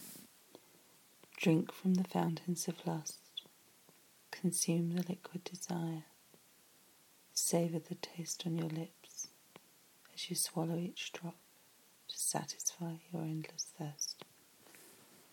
Savour each drop....spoken version